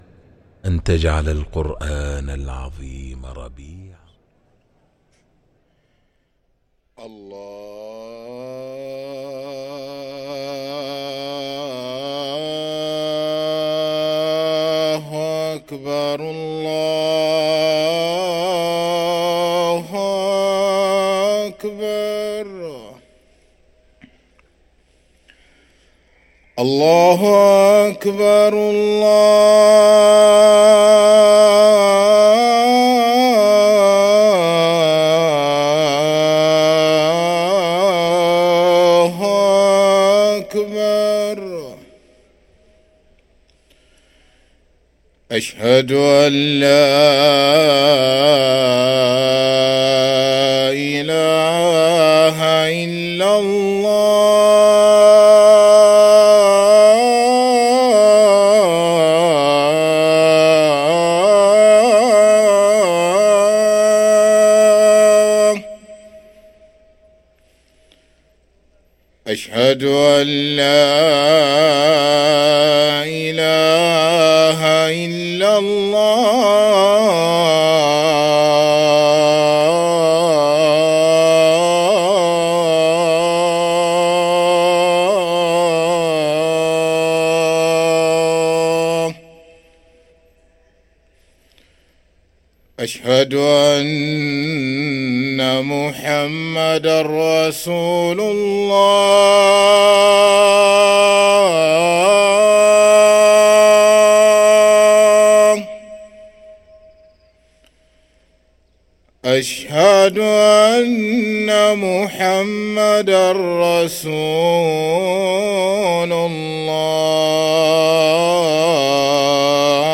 أذان الفجر للمؤذن سعيد فلاته الثلاثاء 21 جمادى الأولى 1445هـ > ١٤٤٥ 🕋 > ركن الأذان 🕋 > المزيد - تلاوات الحرمين